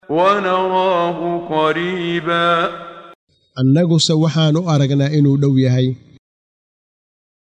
Waa Akhrin Codeed Af Soomaali ah ee Macaanida Suuradda A-Macaarij ( Wadooyinka samada ) oo u kala Qaybsan Aayado ahaan ayna la Socoto Akhrinta Qaariga Sheekh Muxammad Siddiiq Al-Manshaawi.